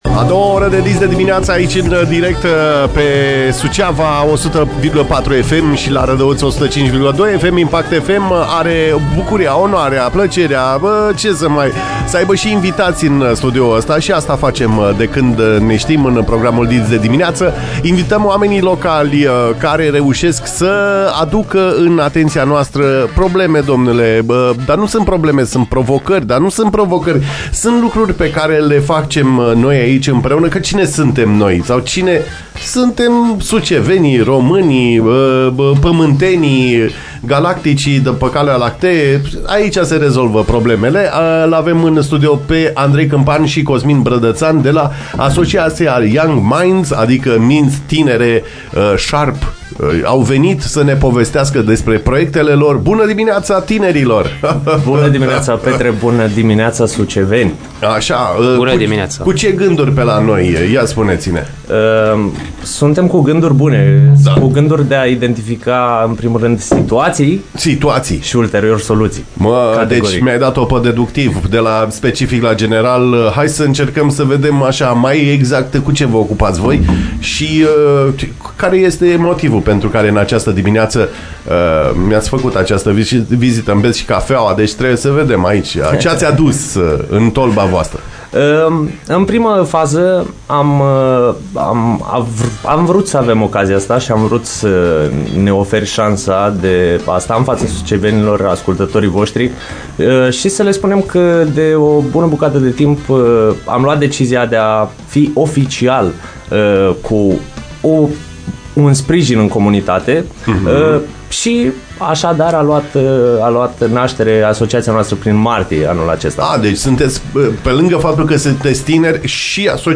Invitați în studioul IMPACT FM, la "Dis de dimineață"